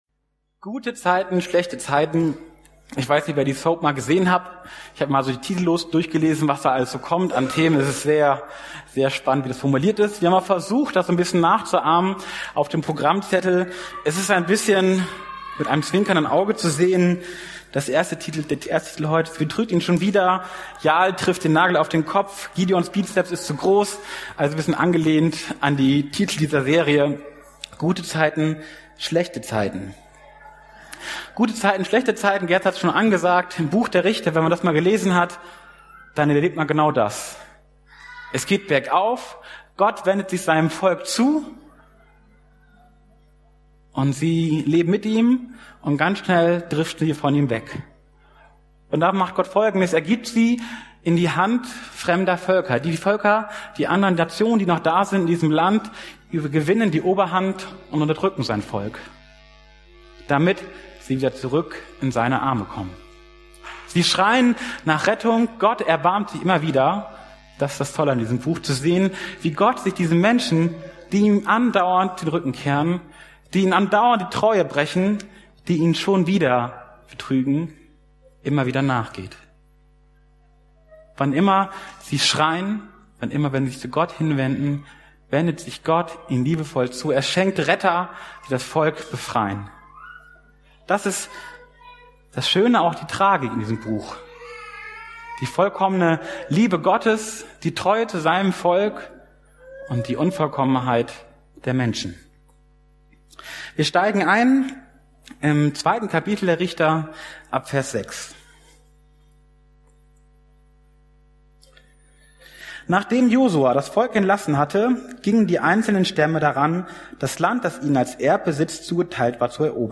Juli 2019 Sie betrügt ihn schon wieder Prediger(-in)